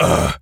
gorilla_hurt_04.wav